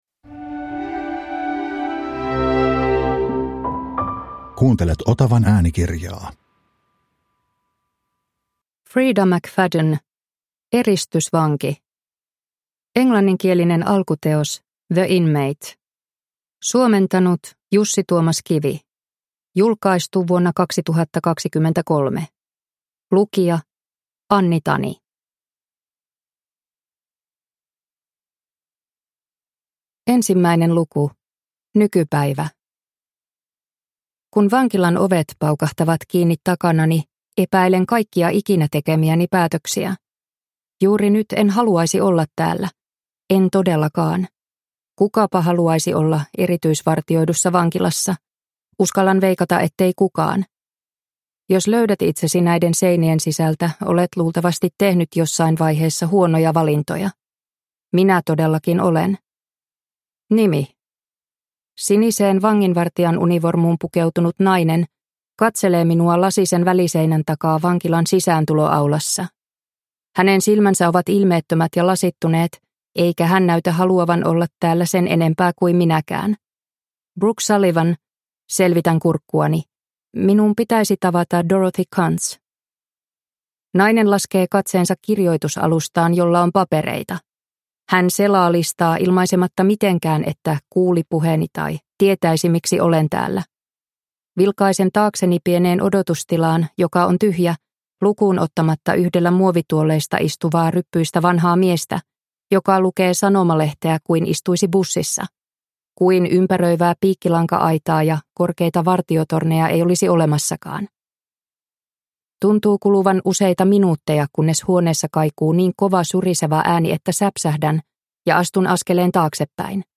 Eristysvanki – Ljudbok – Laddas ner